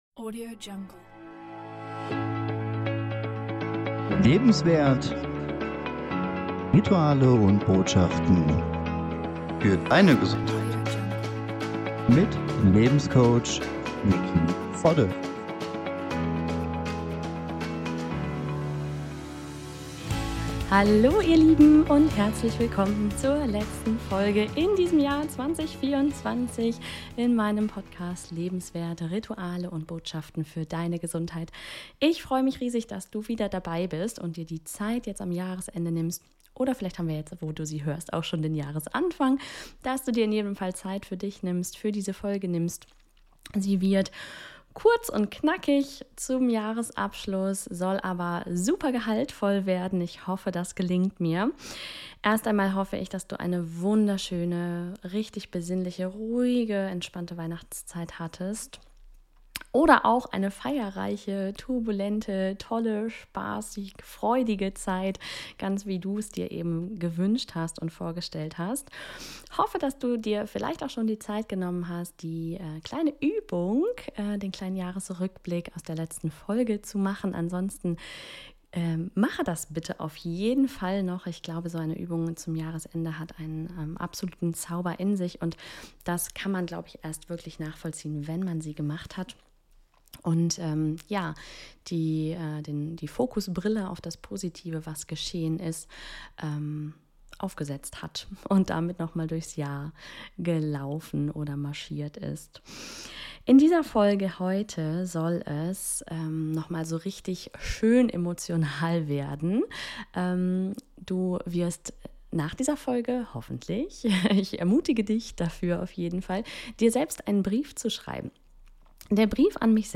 Mit der letzten Folge in 2024 lade ich Dich herzlich ein dir selbst einen ganz besonderen Brief zum Jahresstart 2025 zu schreiben. Lerne liebevoll im Umgang mit dir selbst zu sein und lausche einer einstimmenden kurzen Meditation, um in eine gute Energie dafür zu kommen.